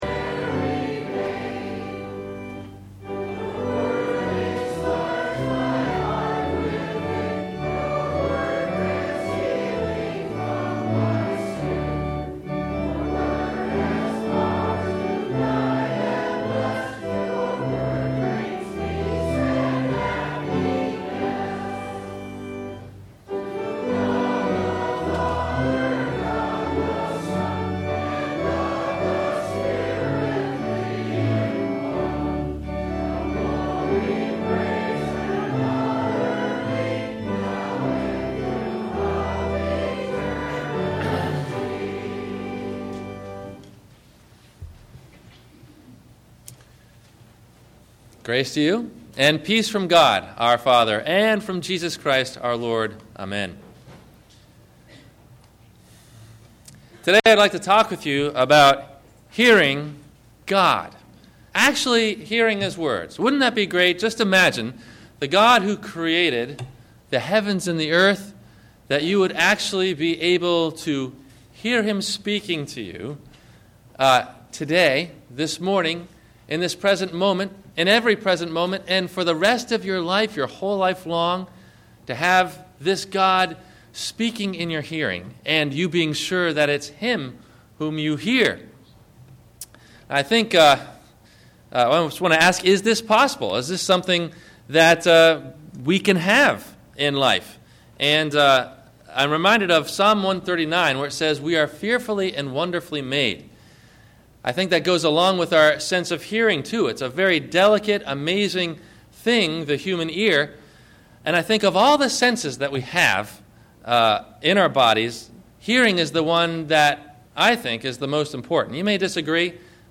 Holy Hearing – Sermon – January 25 2009